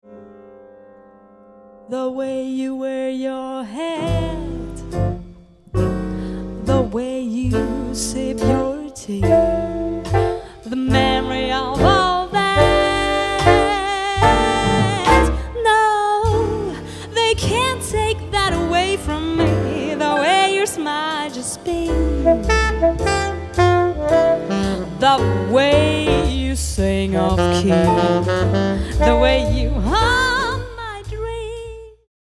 Jazz Sängerin & Songwriterin